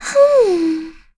Yuria-Vox_Think.wav